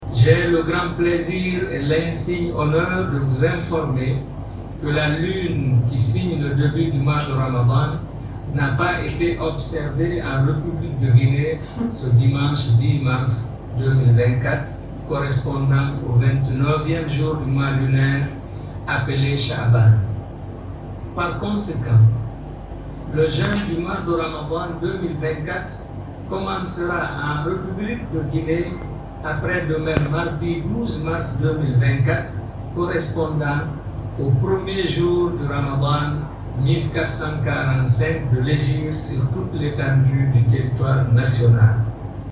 Le secrétariat général des affaires religieuses en République vient d’annoncer que la lune n’a pas été observée par conséquent, le jeûne du mois de ramadan débutera le 12 mars 2024 et non pas lundi comme l’avait cru plus d’un muculmans. Écoutons ensemble ce message audio du secrétariat général des affaires religieuses.👇